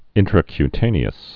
(ĭntrə-ky-tānē-əs)